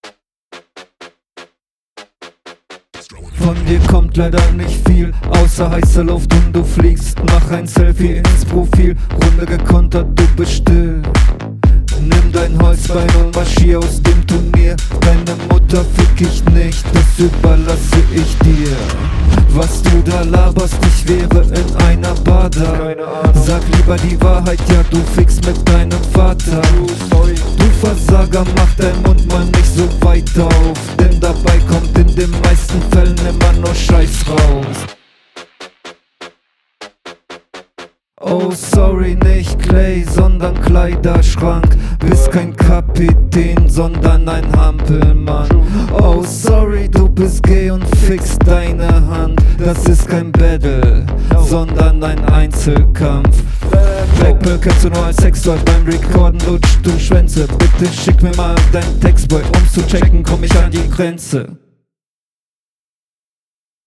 Flow überraschend gut und auch angenehm vom Sound generell. Beat liegt dir.